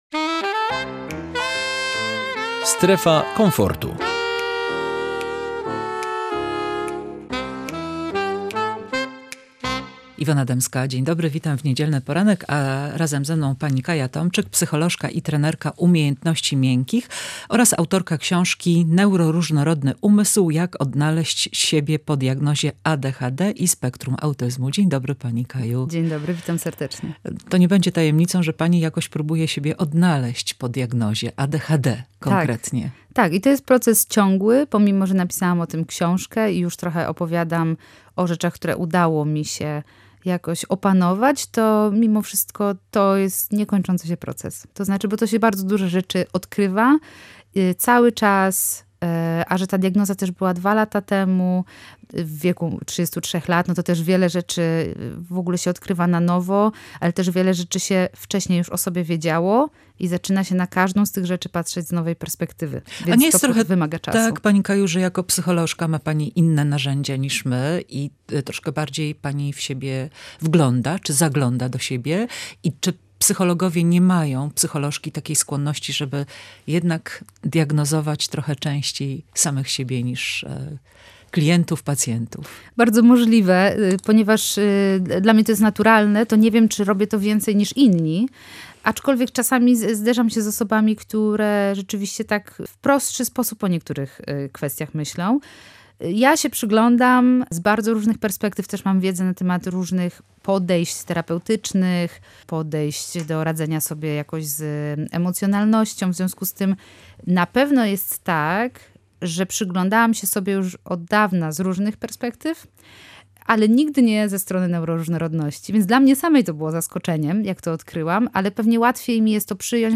Osoby neuroróżnorodne – jak funkcjonują i jak je zrozumieć? Rozmowa z psycholożką ze stwierdzonym ADHD